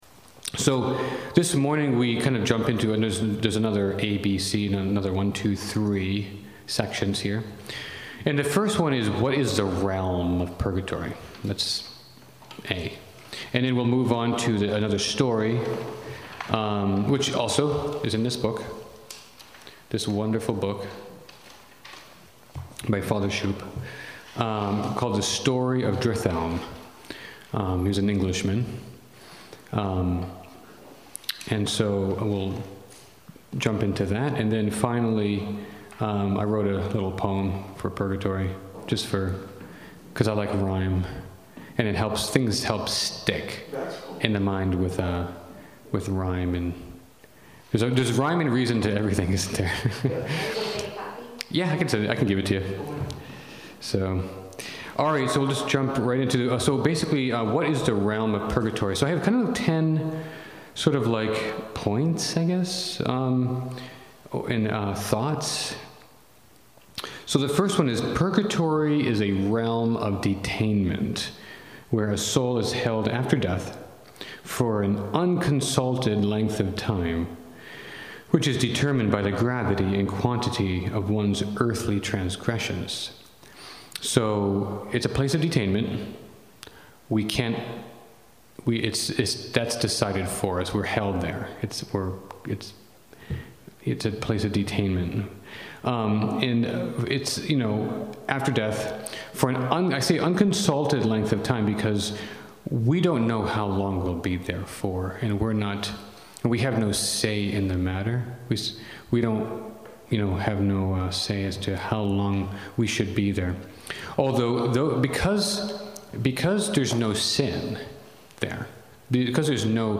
In this second talk, he describes the nature of the realm of purgatory.